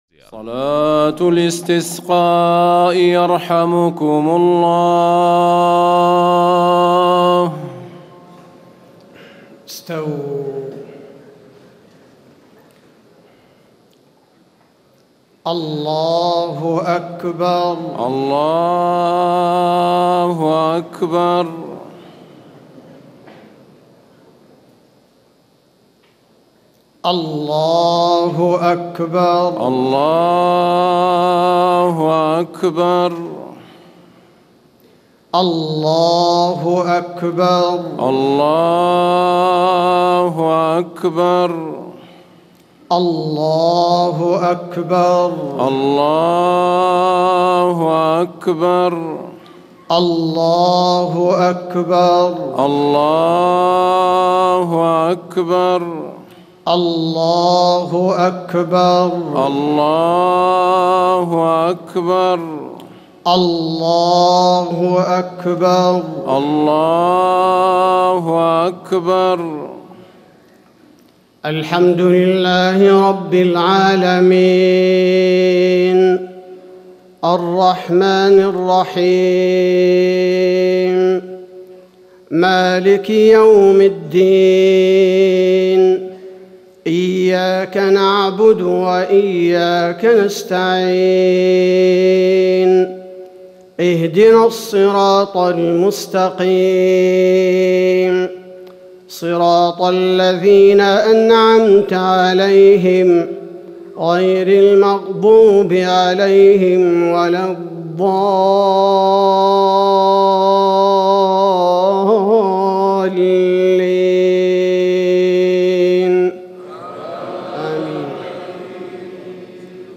صلاة الاستسقاء 26 جمادى الأولى 1439هـ سورتي الأعلى و الغاشية salat alaistisqa Surah Al-A,laa and Al-Ghaashiya > 1439 🕌 > الفروض - تلاوات الحرمين